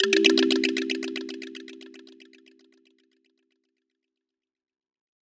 Echoes_A_02.wav